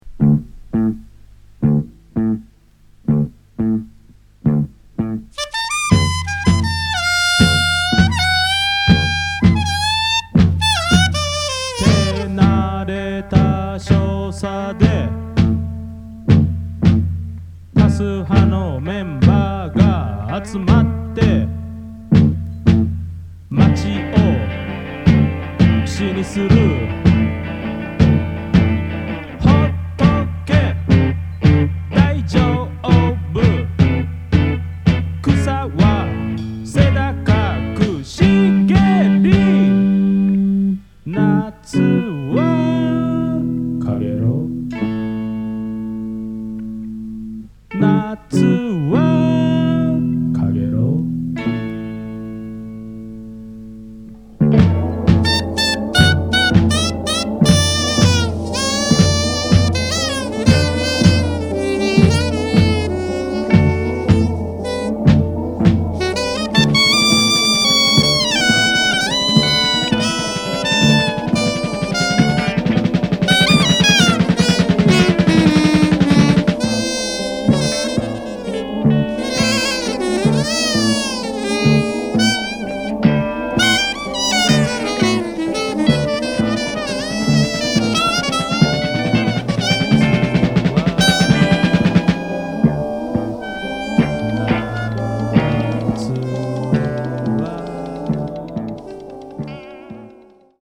即興　脱線パンク　宅録